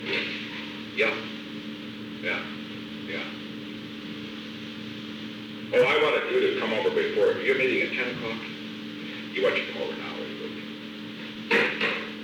Secret White House Tapes
Conversation No. 422-18
Location: Executive Office Building
The President talked with H. R. (“Bob”) Haldeman.